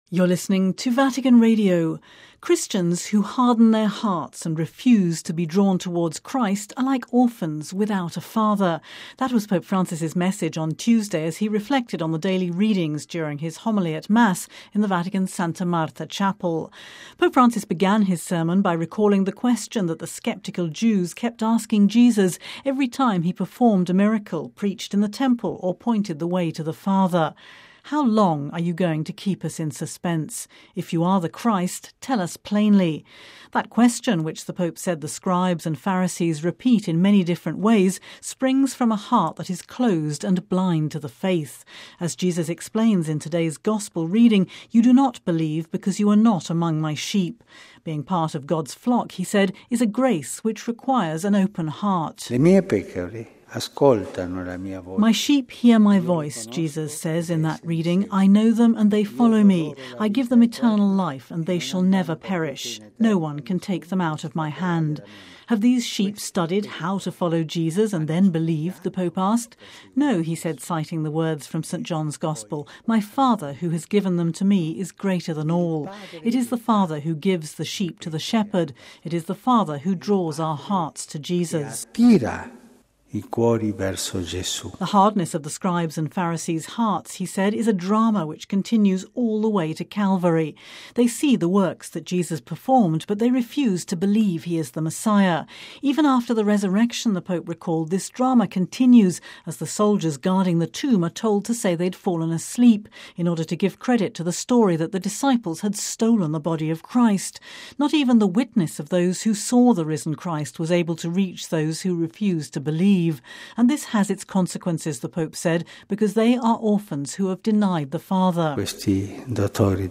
(Vatican Radio) Christians who harden their hearts and refuse to be drawn towards Christ are like orphans, without a father. That was Pope Francis message on Tuesday as he reflected on the daily readings during his homily at Mass in the Vatican’s Santa Marta chapel.